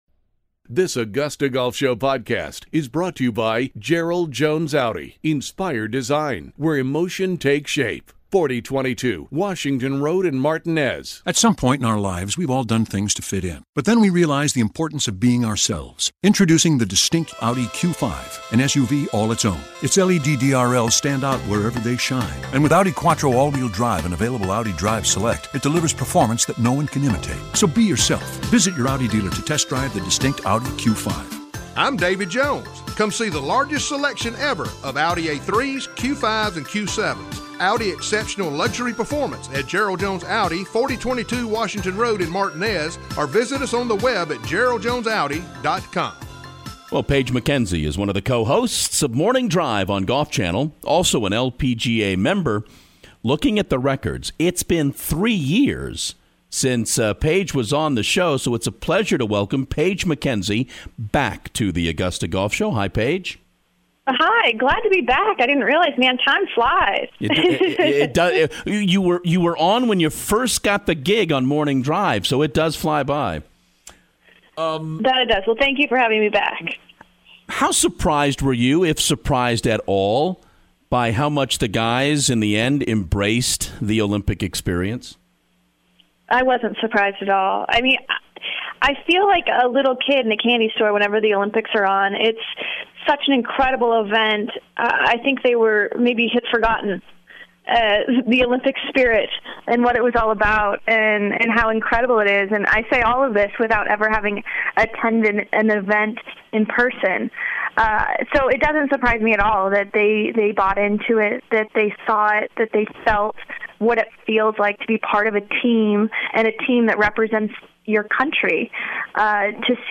Golf Channel’s Paige Mackenzie: The Augusta Golf Show Interview
Golf Channel Morning Drive co-host Paige Mackenzie is on the show to talk about the impact golf in the Olympics will have on the game